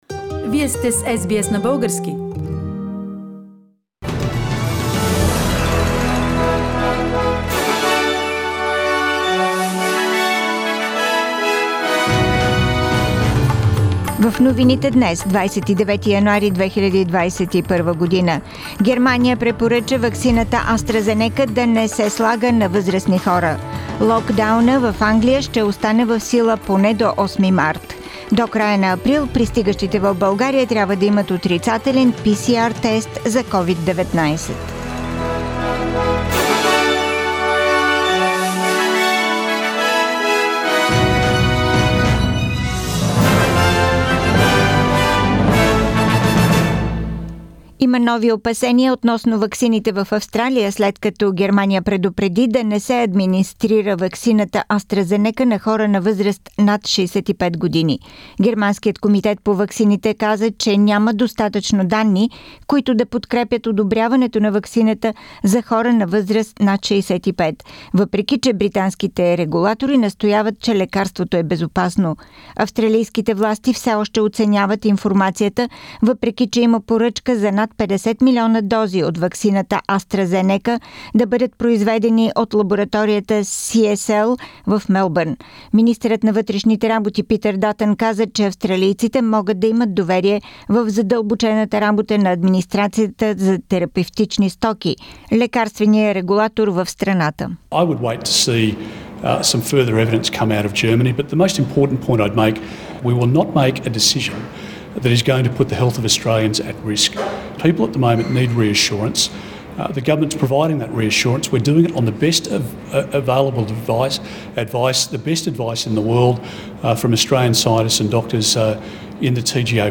Weekly Bulgarian News – 29th January 2021